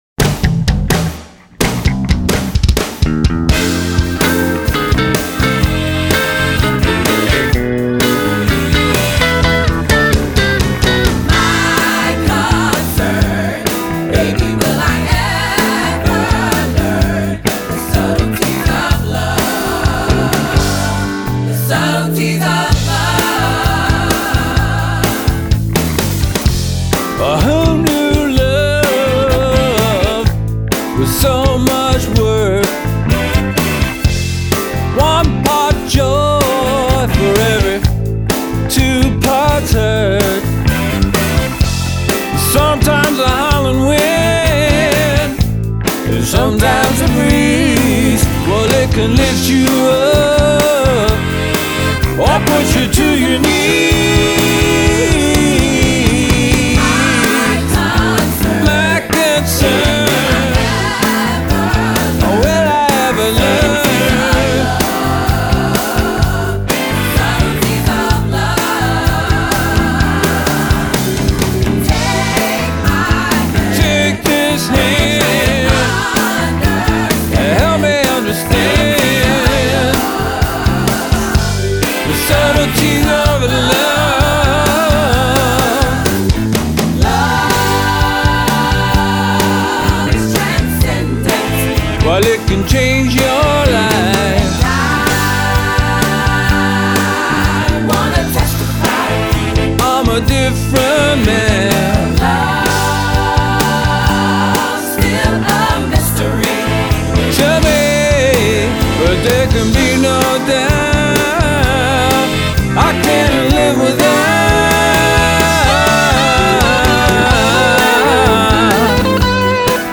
A soulful R&B style song
keys